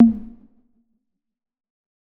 Tom_E1.wav